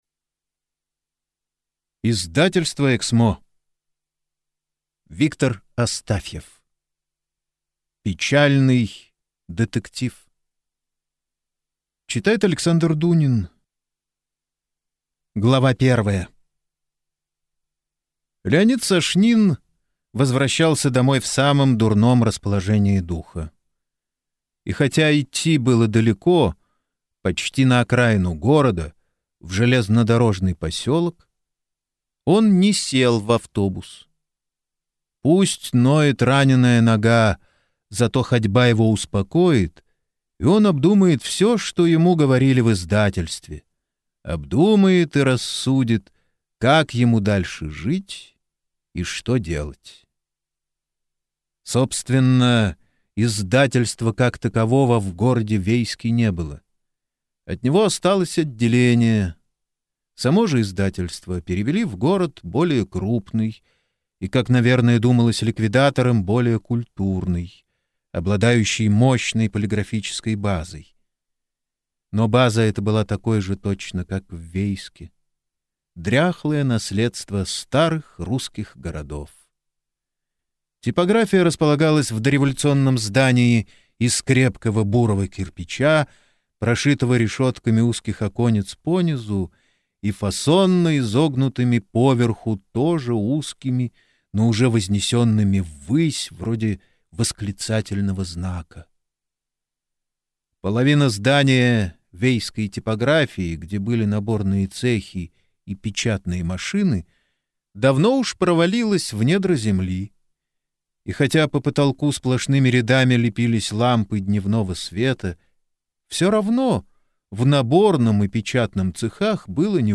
Аудиокнига Печальный детектив | Библиотека аудиокниг